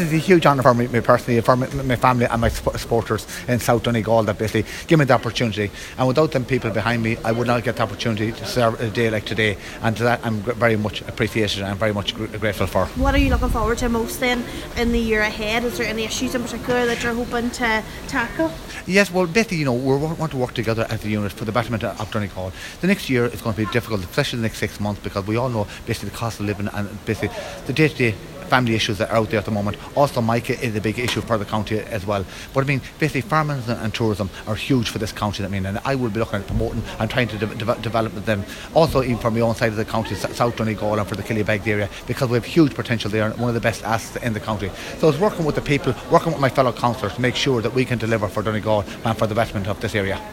Cllr Naughton says it’s a huge honour: